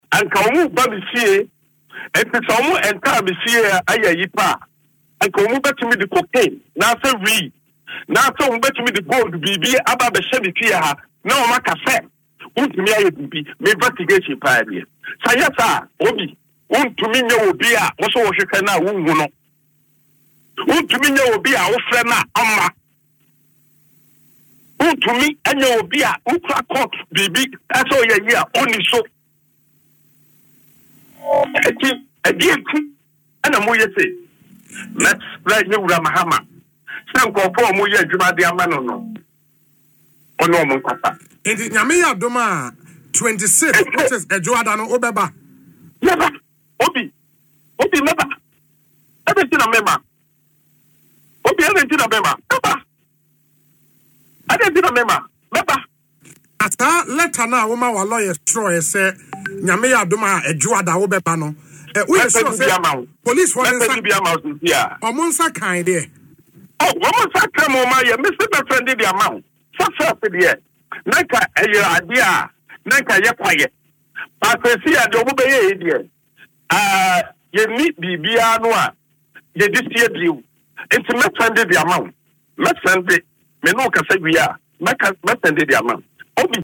He made these claims in an interview on Asempa FM’s Ekosii Sen, asserting that the operatives would have succeeded if they had managed to enter his residence.